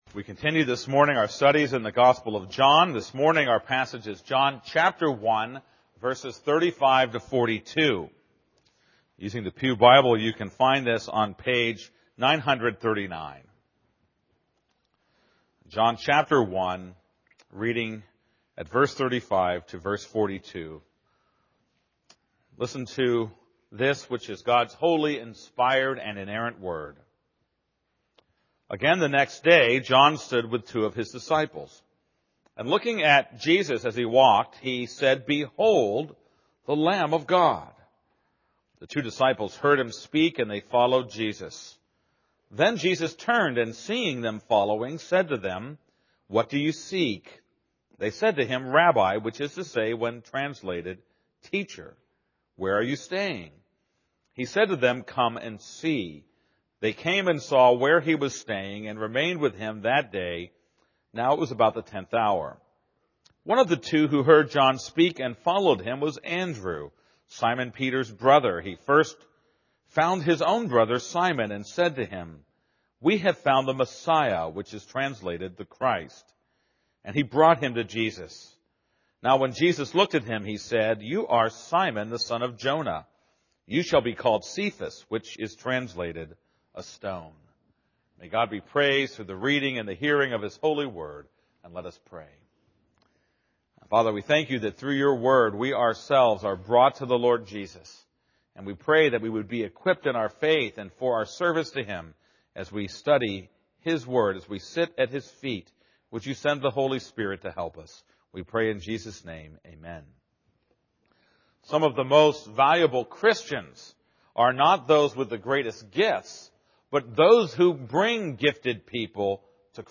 This is a sermon on John 1:35-42.